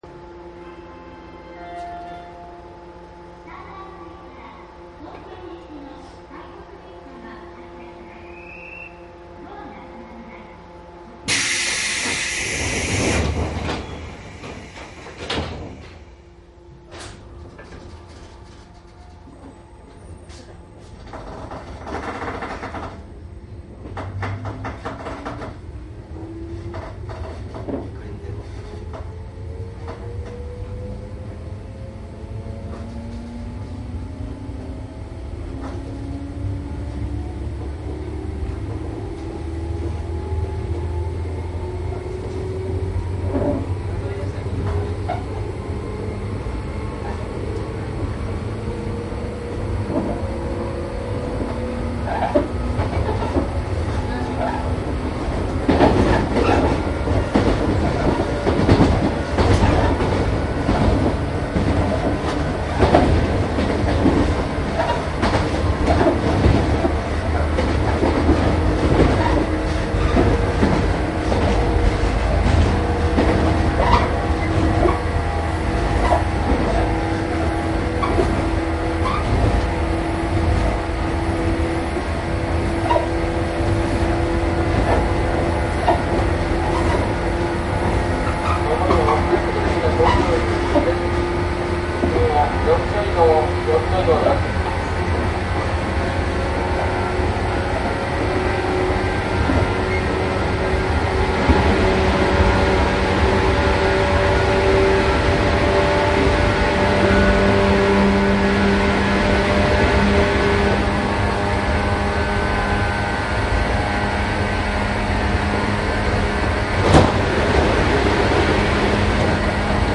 JR成田線回り・総武本線 113系走行音
マスター音源はデジタル44.1kHz16ビット（マイクＥＣＭ959）で、これを編集ソフトでＣＤに焼いたものです。